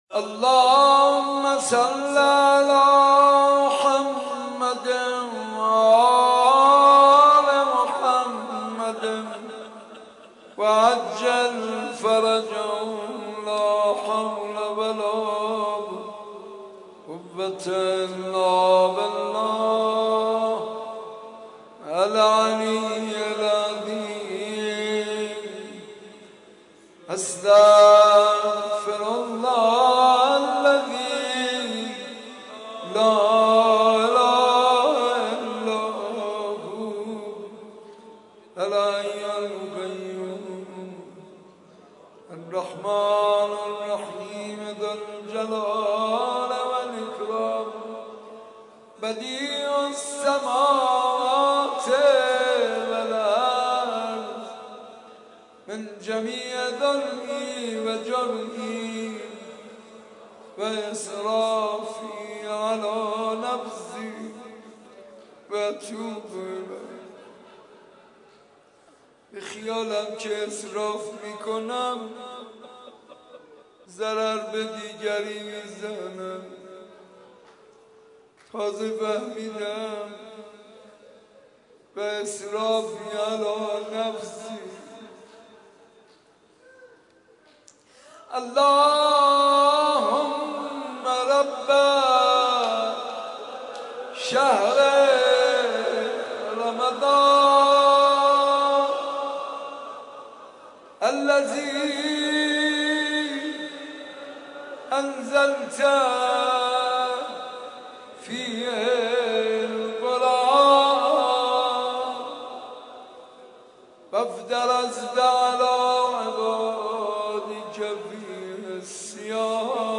زمزمه دعای کمیل حضرت امیرالمومنین علیه السلام را در این شب جمعه ماه مبارک رمضان با نوای حاج منصور ارضی می شنوید.